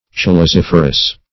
Search Result for " chalaziferous" : The Collaborative International Dictionary of English v.0.48: Chalaziferous \Chal`a*zif"er*ous\, a. [Chalaza + -ferous.]
chalaziferous.mp3